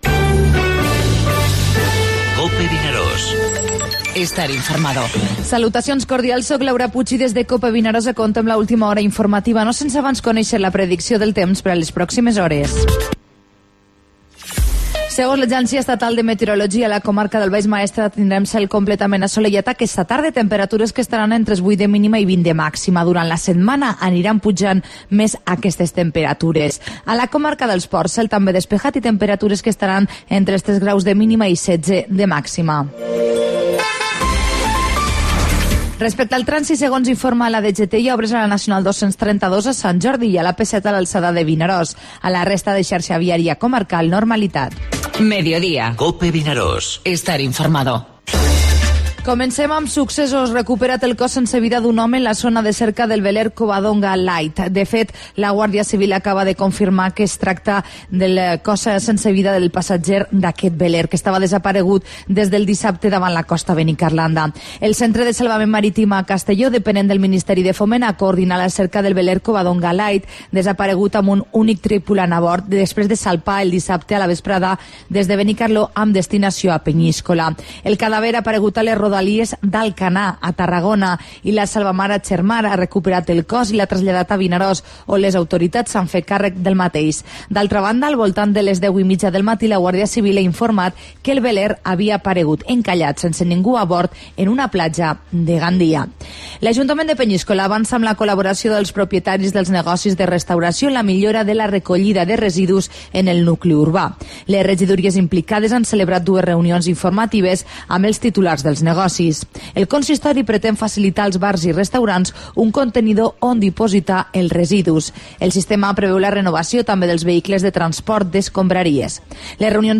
Informativo Mediodía COPE al Maestrat (10/4/17)